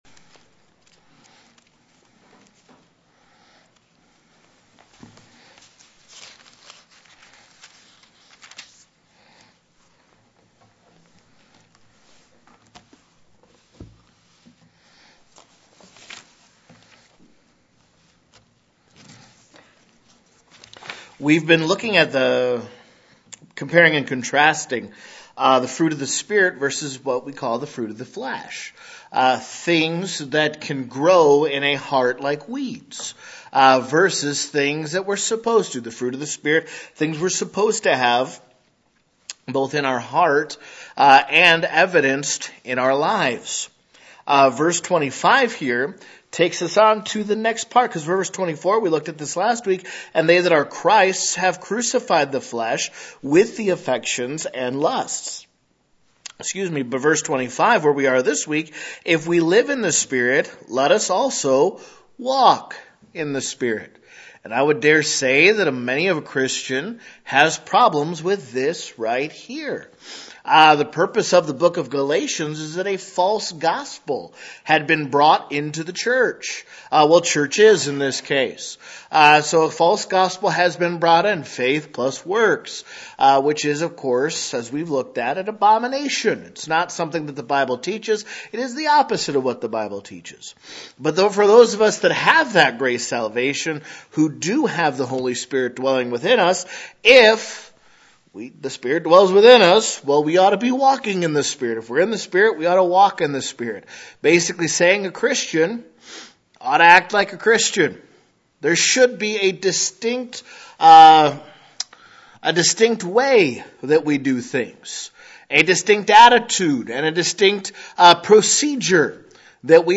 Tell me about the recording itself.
May 2023 - Weekly Sunday Services This page presents the Sunday School lessons recorded at Home Missionary Baptist Church during our Sunday Services.